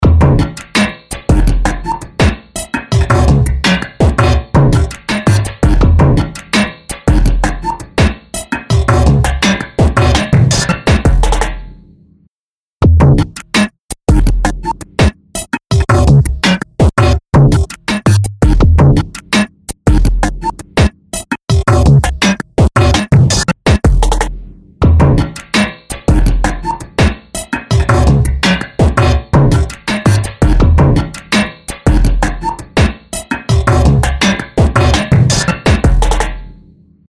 Tube Amp-style Spring Reverb
Spring | Electronic Loop | Preset: Imaginary Telephone Booth
Spring-Eventide-Electronic-Loop-Imaginary-Telephone-Booth.mp3